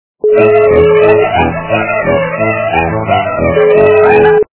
Смешные